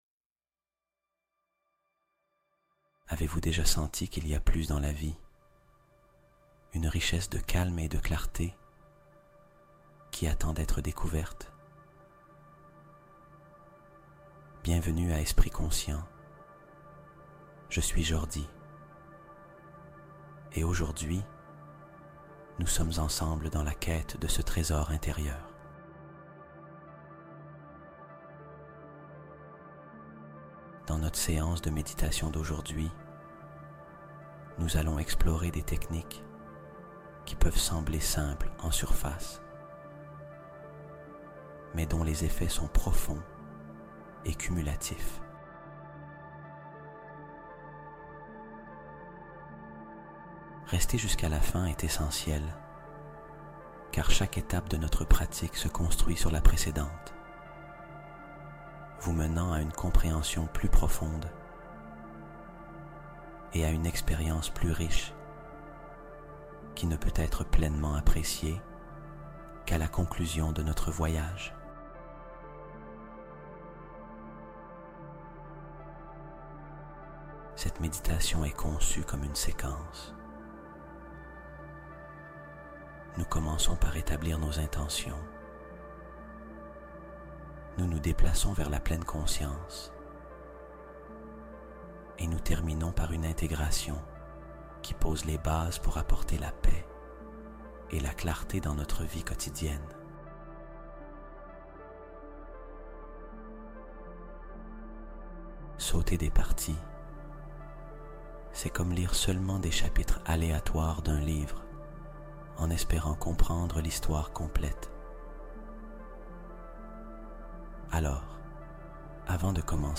Sommeil de Plénitude : Méditation guidée pour la manifestation nocturne